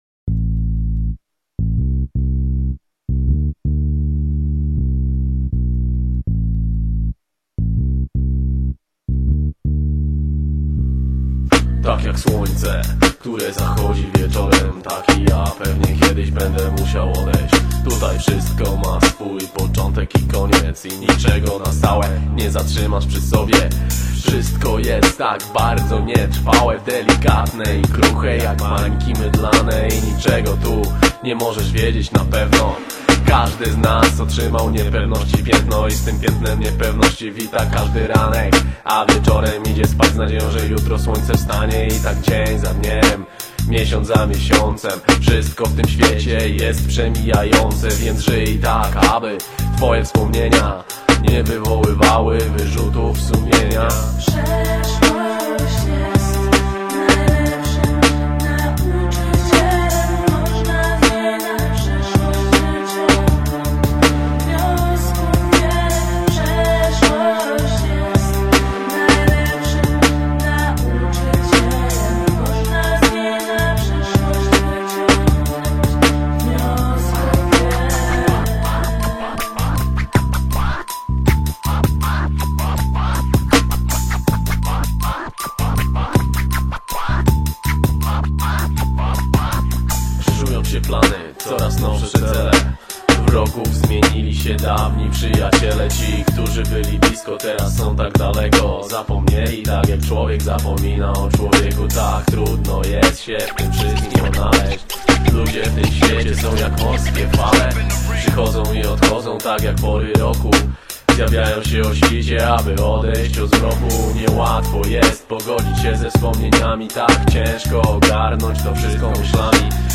Z 2000 roku chyba - cały utwór - Hip-hop / Rap / R'N'B